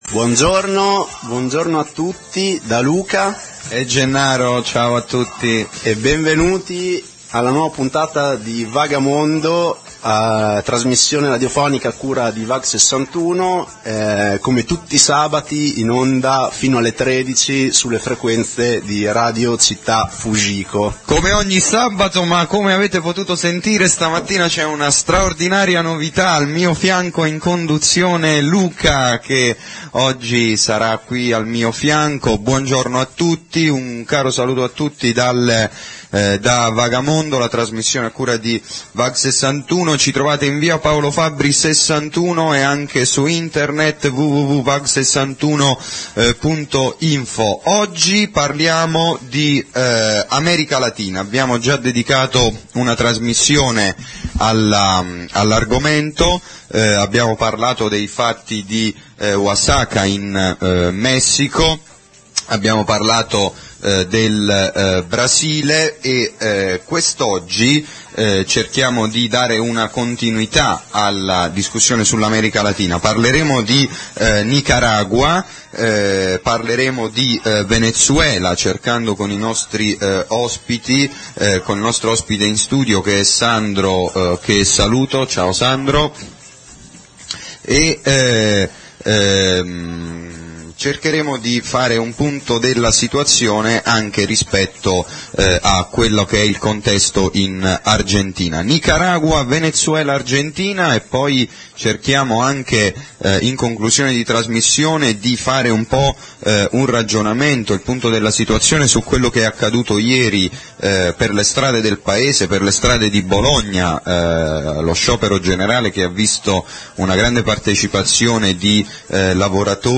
Vag61 - Scarica la puntata del 18 novembre '06 - Secondo appuntamento con le narrazioni dalla viva voce di chi ha attraversato i territori e i conflitti di un continente in fermento. In questa puntata parliamo di Venezuela, Nicaragua, Argentina.